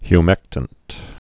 (hy-mĕktənt)